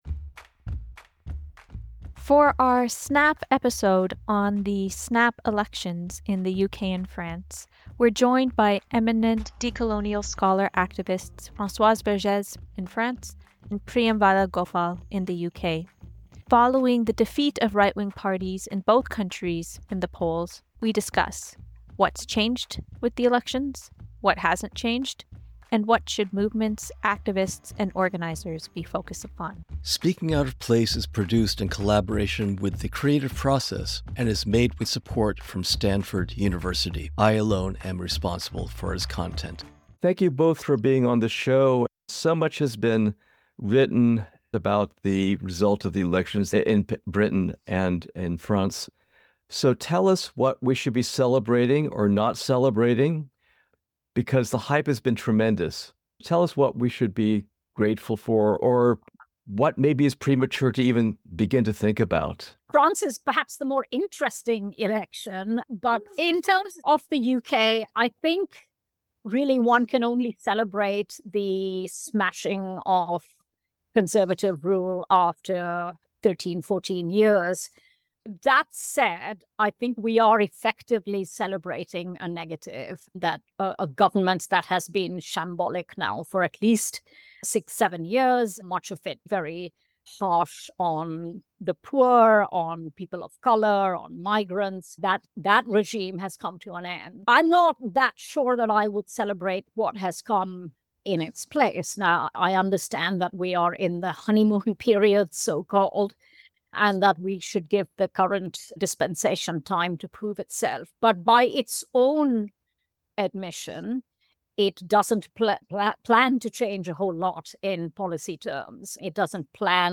For this installment, we've relocated the podcast studio to the prestigious Copenhagen Business School.